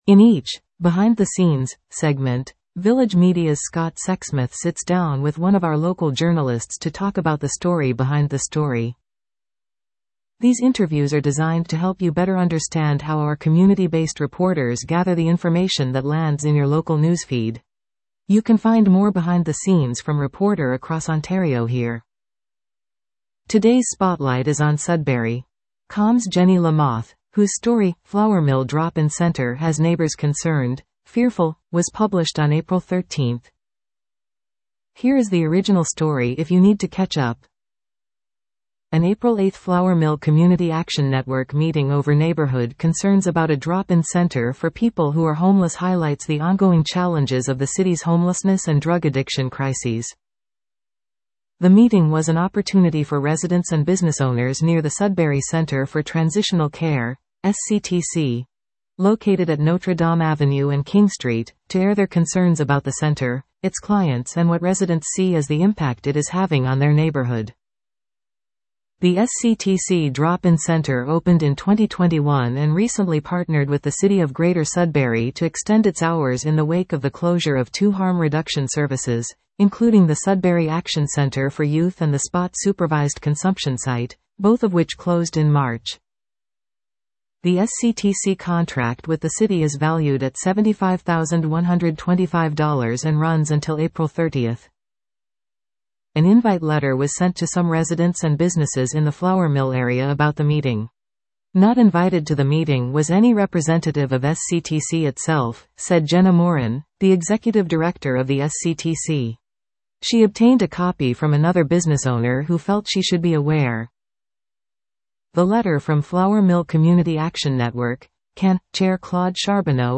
These interviews are designed to help you better understand how our community-based reporters gather the information that lands in your local news feed.